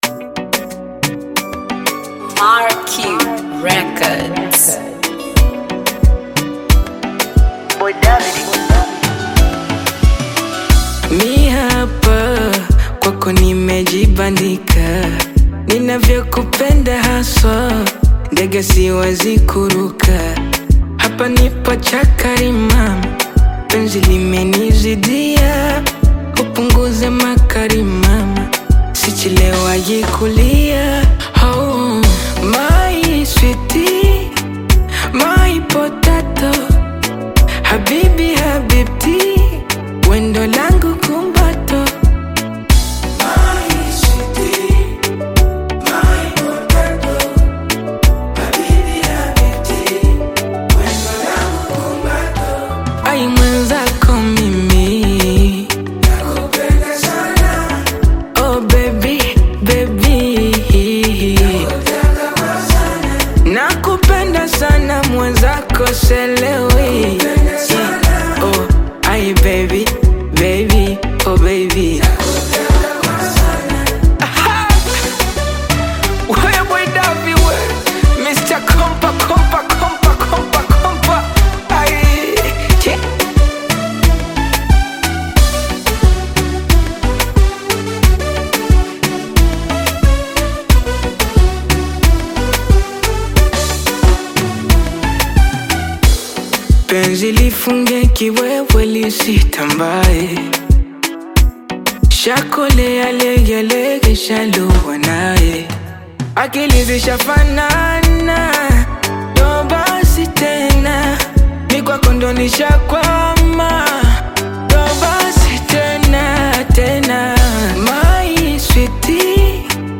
Bongo Flava music track
Bongo Flava song